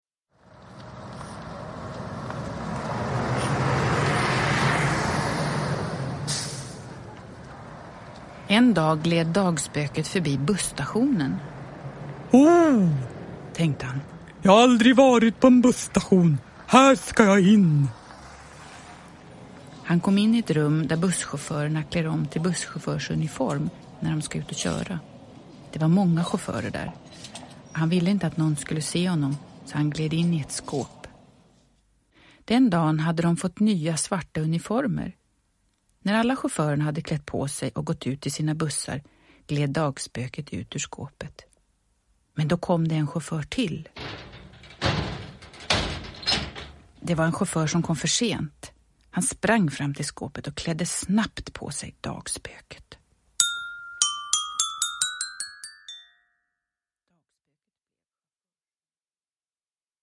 Dagspöket kör buss – Ljudbok – Laddas ner
Produkttyp: Digitala böcker